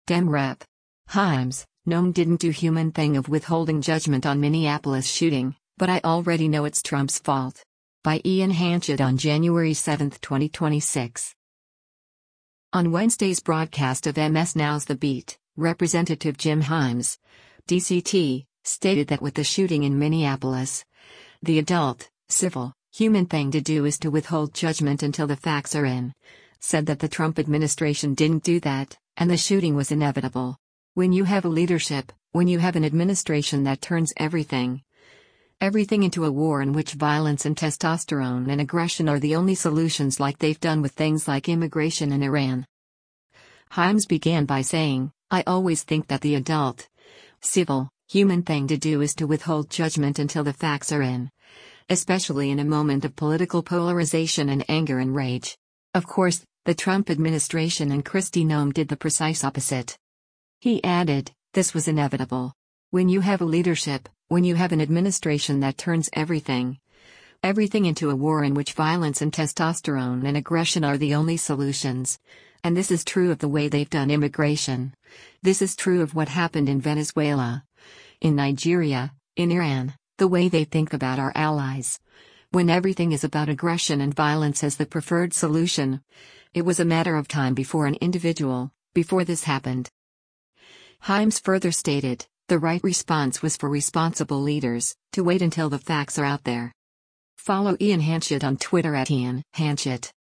On Wednesday’s broadcast of MS NOW’s “The Beat,” Rep. Jim Himes (D-CT) stated that with the shooting in Minneapolis, “the adult, civil, human thing to do is to withhold judgment until the facts are in,” said that the Trump administration didn’t do that, and the shooting “was inevitable. When you have a leadership, when you have an administration that turns everything, everything into a war in which violence and testosterone and aggression are the only solutions” like they’ve done with things like immigration and Iran.